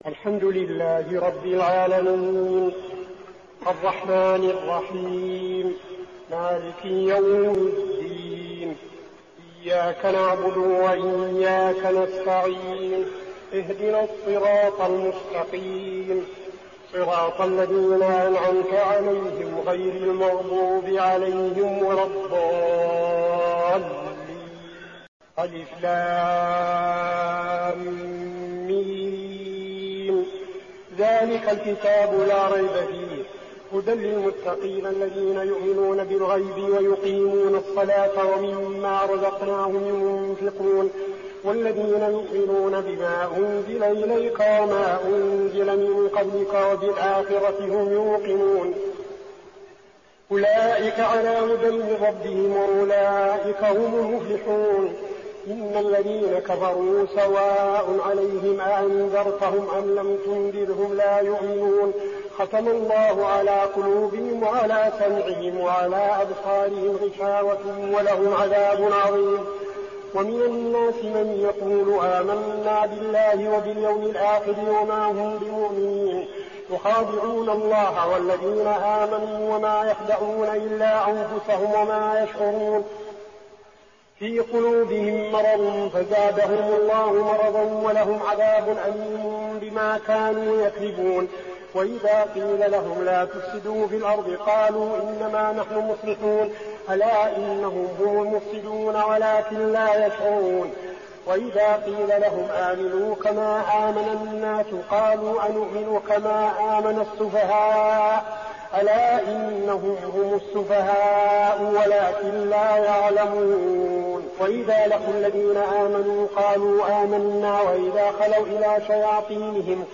صلاة التراويح ليلة 1-9-1402هـ سورة البقرة 1-77 | Tarawih prayer Surah Al-Baqarah > تراويح الحرم النبوي عام 1402 🕌 > التراويح - تلاوات الحرمين